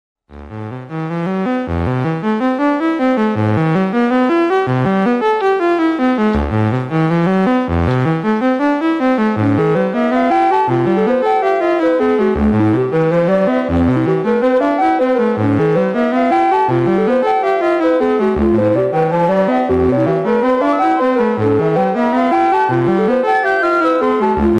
Corridos tumbados en Drak Fantasy sound effects free download